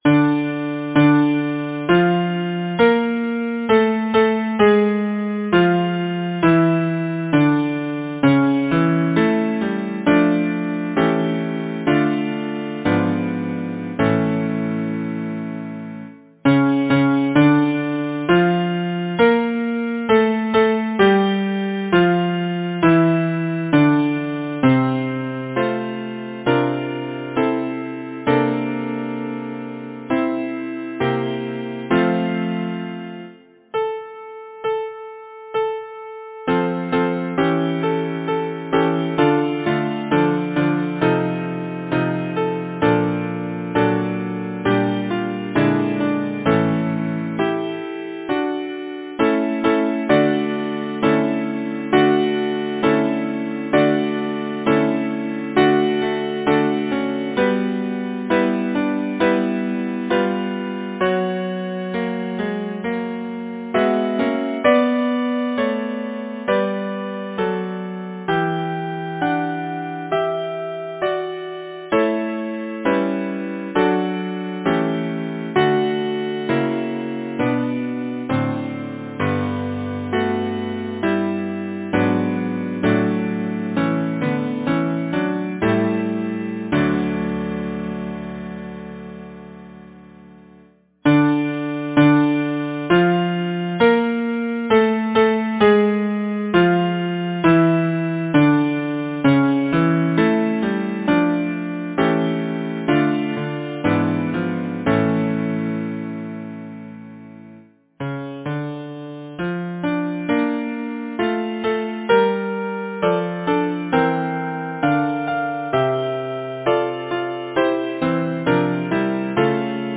SATB, divisi Genre: Sacred, Partsong
Language: English Instruments: A cappella
First published: 1874 Stanley Lucas, Weber & Co. Description: Four Part Songs for Mixed Voices, No. 3